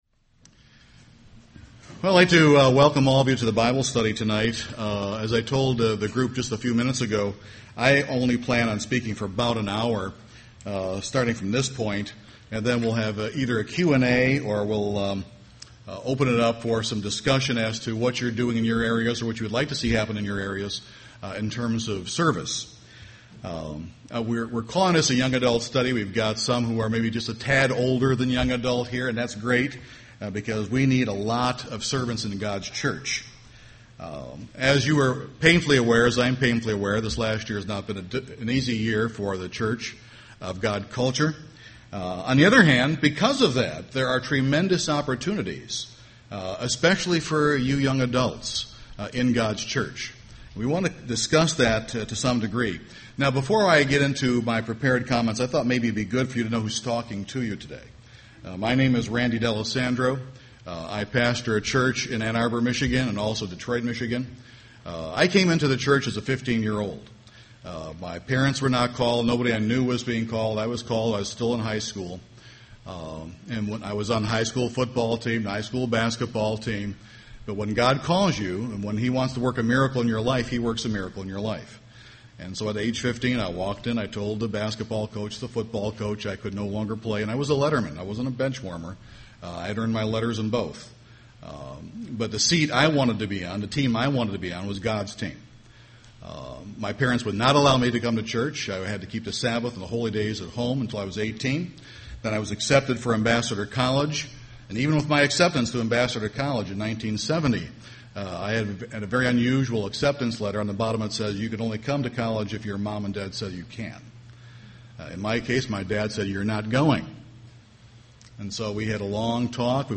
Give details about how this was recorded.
This sermon was given at the Maui, Hawaii 2011 Feast site.